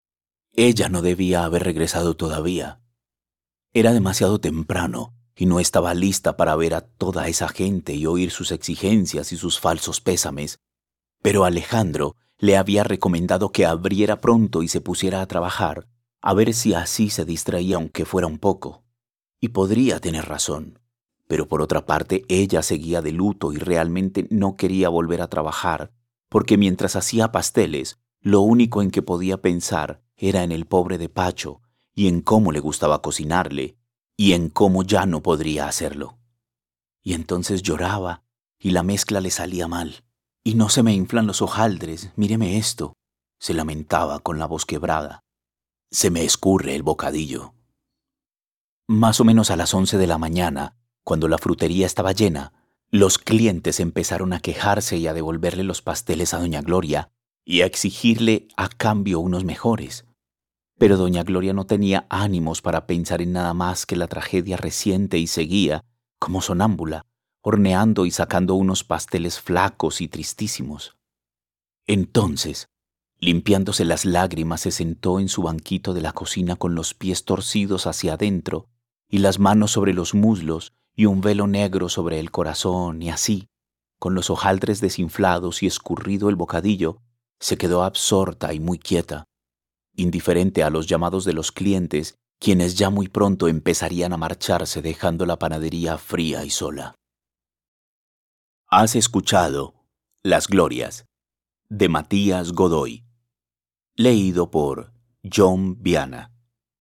Fragmentos de audiolibros